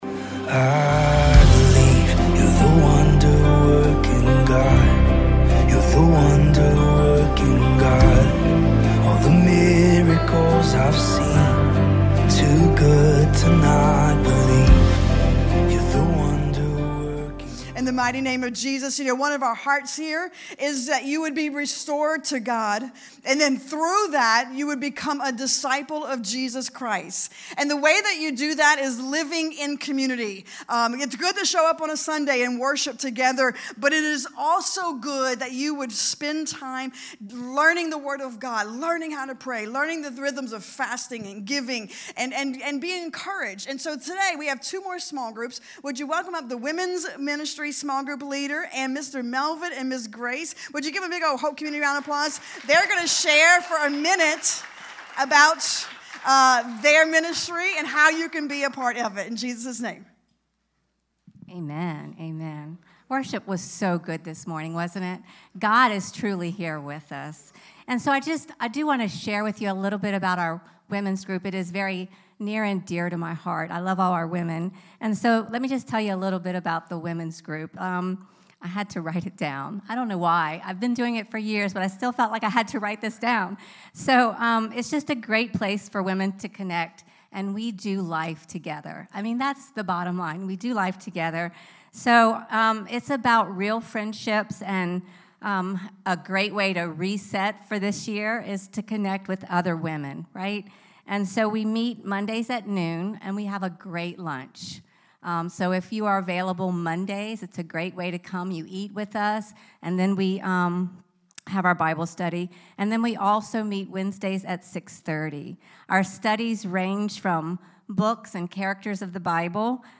2026 Current Sermon Hope...